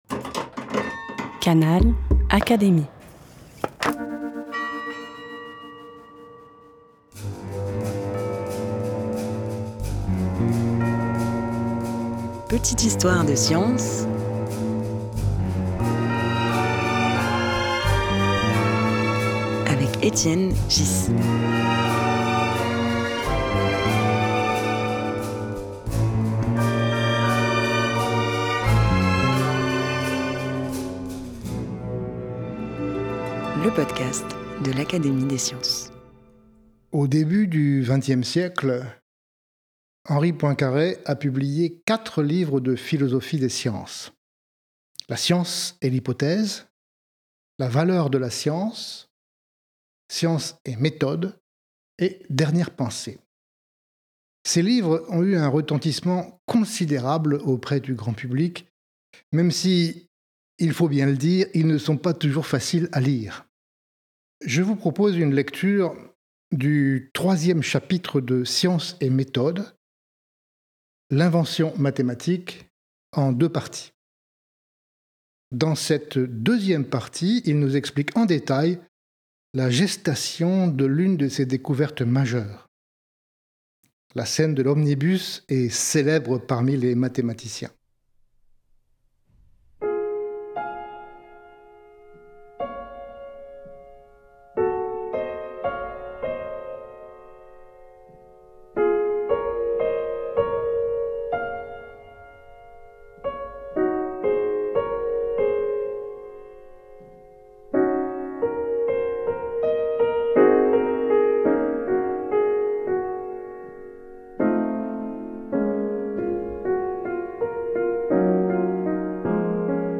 Dans cet épisode, Étienne Ghys poursuit la lecture du chapitre sur l’invention mathématique extrait de Science et méthode (1908).
Extrait lu par Étienne Ghys, Secrétaire perpétuel de l’Académie des sciences, tiré du livre Science et Méthode, par Henri Poincaré (1908).